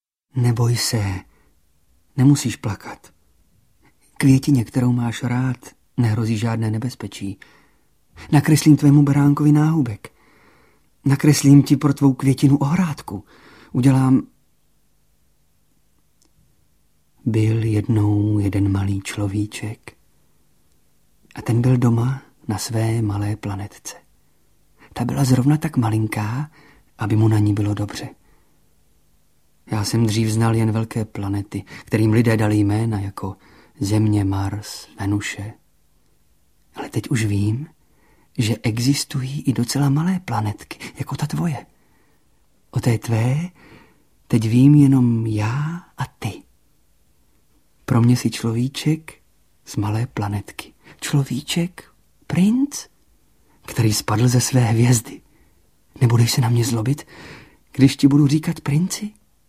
Nahrávka je komponována jako pásmo složené z úryvků hercova repertoáru v 90. letech minulého století (v divadle Kašpar, Minor, ve Viole, Lyře Pragensis, v čajovně Herba Magica i v produkci AudioStory), z literárních ukázek a z Janovy autentické, niterné zpovědi, která jednotlivé úryvky uvádí a spojuje. Poslechnete si ukázky z Malého prince, Alchymisty, Proroka, Jonathana Livingstona Racka, Radúze a Mahuleny, verše Václava Hraběte, Jiřího Ortena, nebo Jana Skácela atd.
Čte: Jan Potměšil